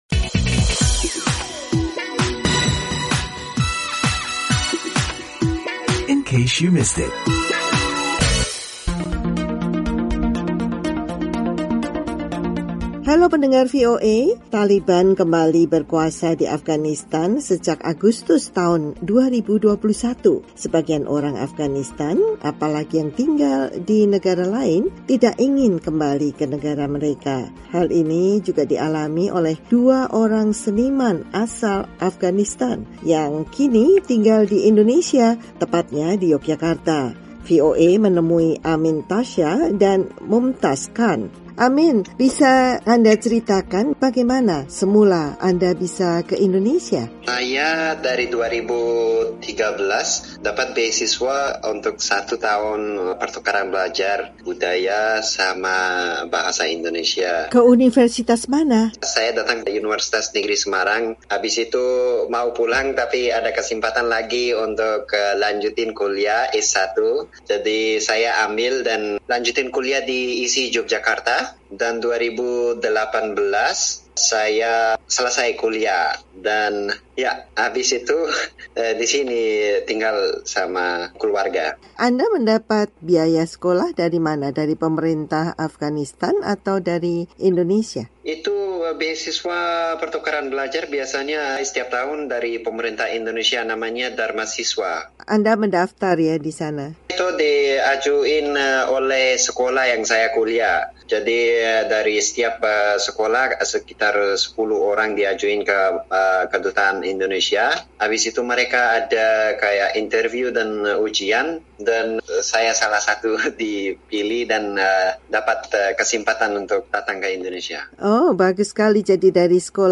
berbincang dengan dua diaspora Afghanistan di Indonesia, yang berprofesi sebagai pelukis, yang enggan pulang ke tanah air mereka.